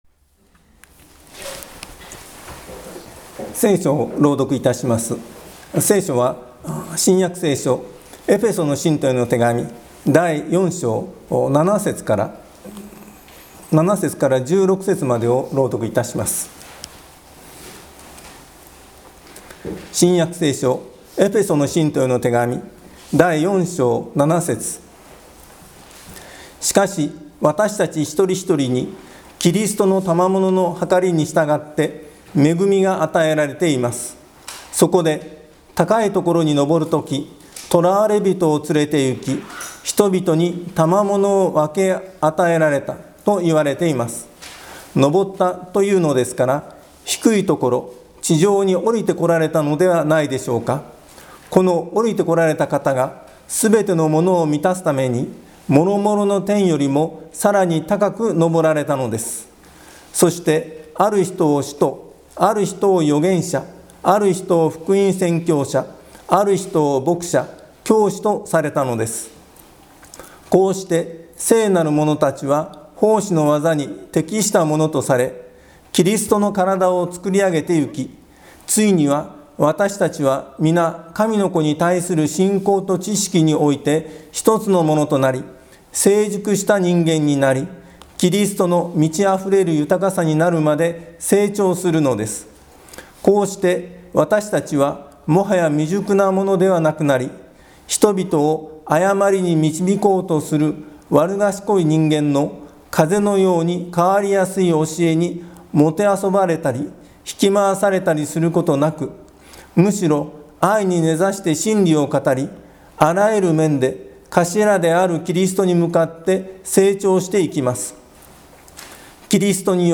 毎週日曜日に行われ礼拝説教のアーカイブデータです。
Youtubeで直接視聴する 音声ファイル 礼拝説教を録音した音声ファイルを公開しています。